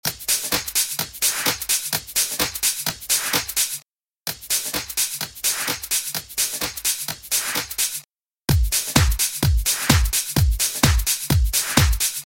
Tip 2: Sidechaining Your Percussion
With a release time of 500ms the compressor has ‘let go’ by the time the next kick drum hits and we get a nice subtle pumping sound. In this audio example you can hear the hats/percussion before and after sidechaining is applied, and then with the rest of the beat.